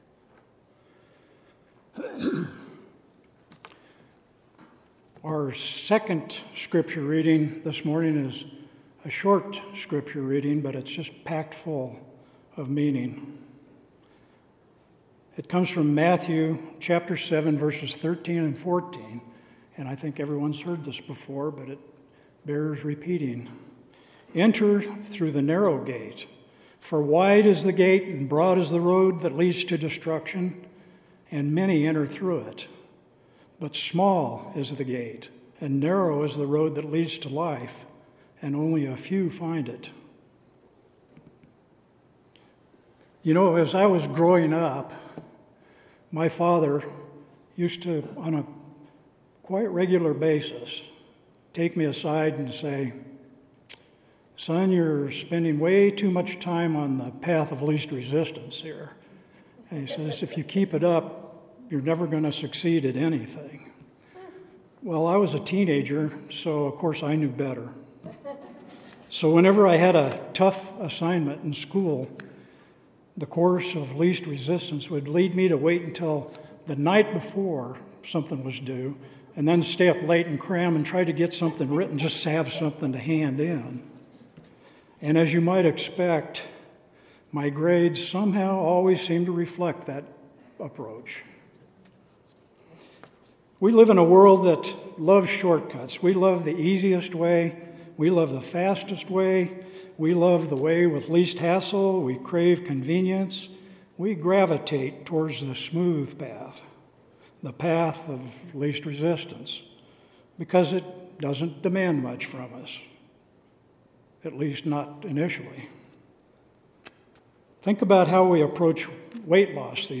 Sermon – August 17, 2025 – “Path of Least Resistance”